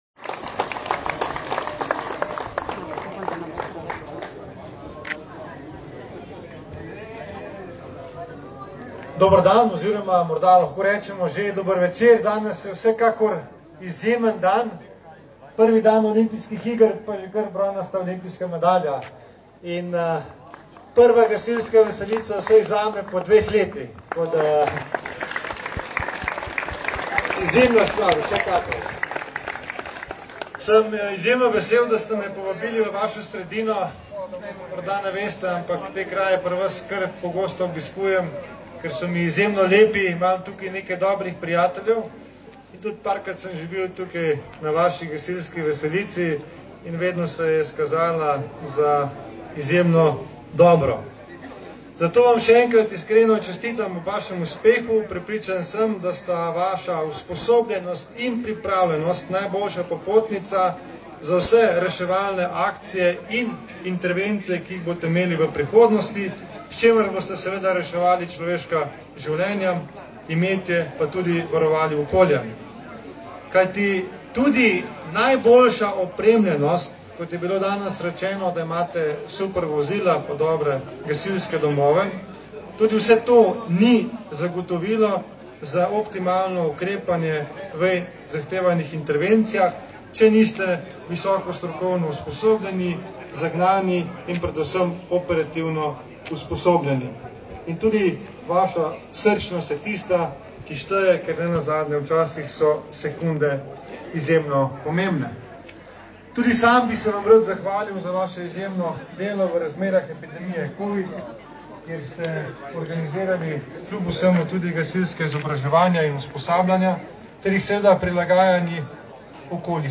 Na Svetem Gregorju nad Ribnico je v soboto, 24. julija, potekala slovesnost ob podelitvi priznanj in činov novim operativnim gasilkam in gasilcem ter gasilskim pripravnikom ob uradnem sprejemu v gasilske vrste.
Govor ministra za obrambo mag. Mateja Tonina na Svetem Gregorju